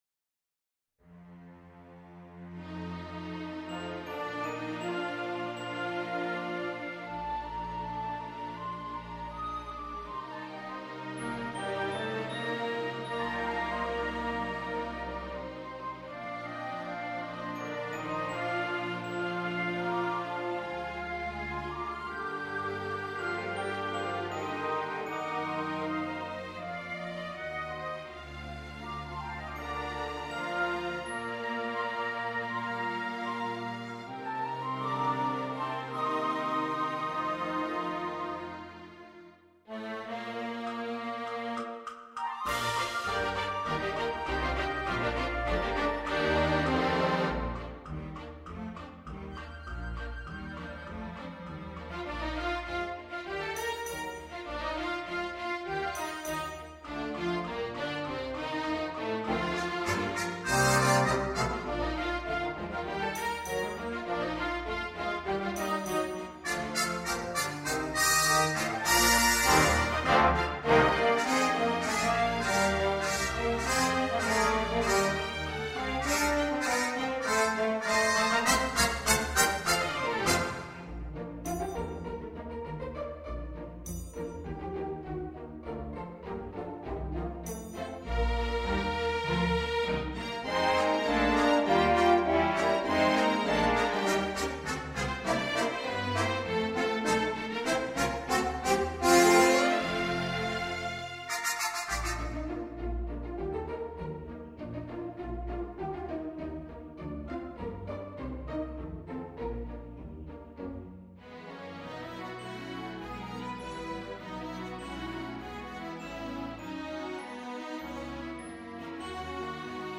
Santa-Claus-is-Coming-Backing.mp3